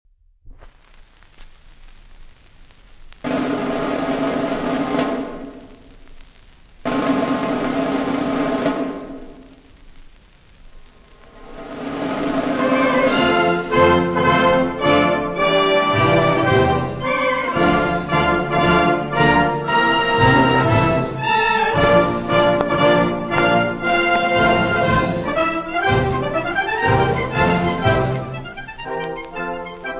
• Banda dell'Arma dei Carabinieri Reali [interprete]
• musica per banda
• ouverture
• trascrizioni musicali